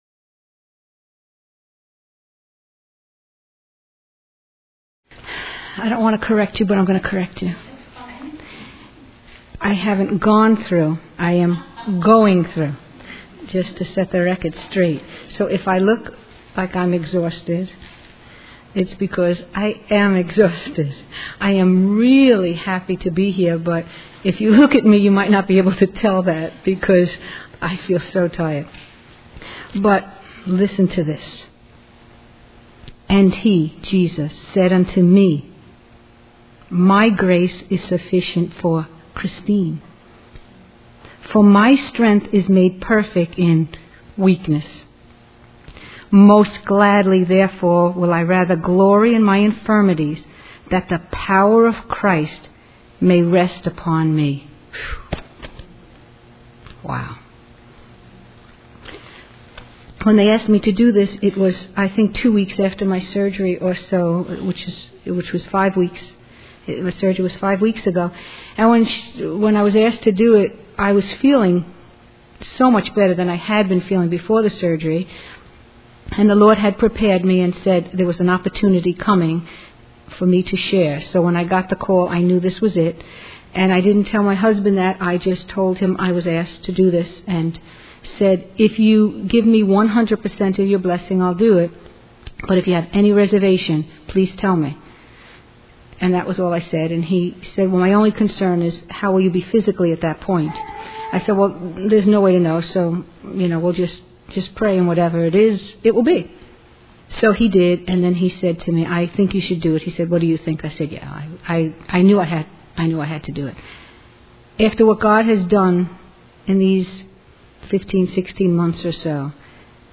A spirit-filled preaching by a bishop from Uganda Not Alone in Suffering (Audio) by Mother Baselia Schlink of Evangelical Sisterhood of Mary Why does God Allow Suffering?